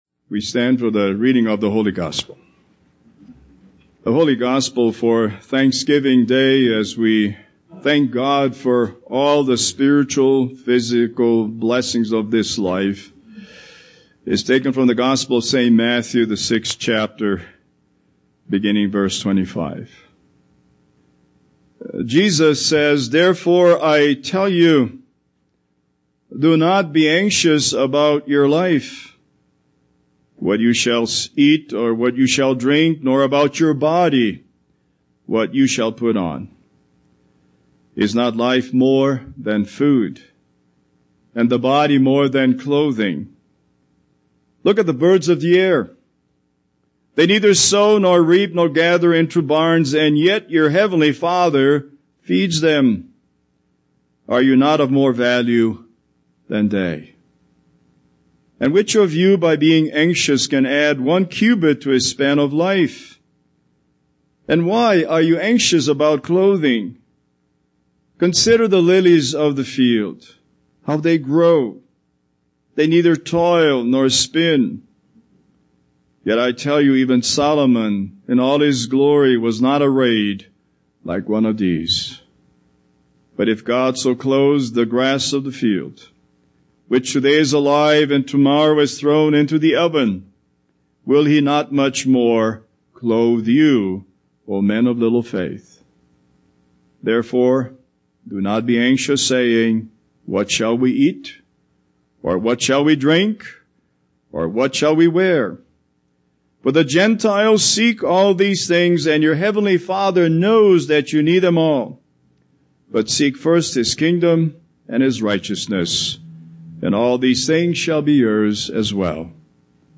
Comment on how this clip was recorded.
Holiday Sermons Passage: Matthew 6:25-34 Service Type: Thanksgiving Service